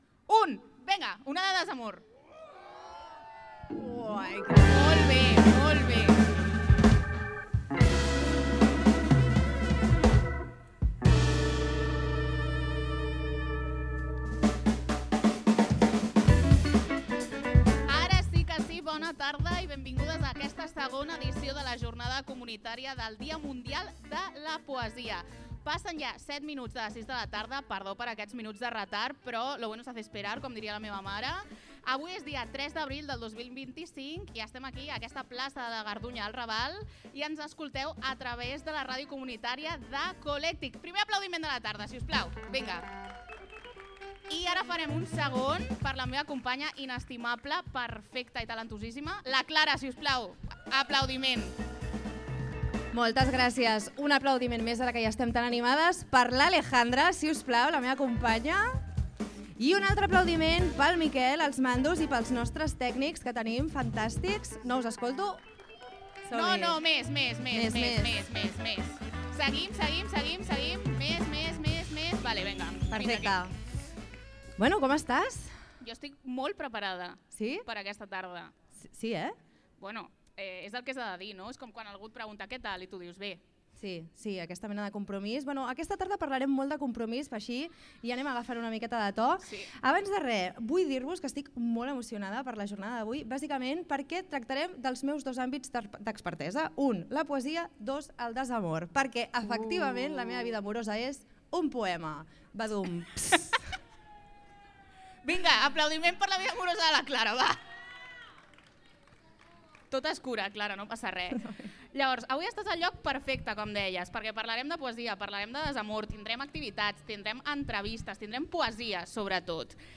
Així doncs al programa què podeu escoltar en aquesta entrada, podreu viure i escoltar la jornada comunitària del dia de la poesia, on vam tenir i representants de diferents equipaments, col.lectius i associacions del barri parlant sobre el desamor i l’amor a la comunitat!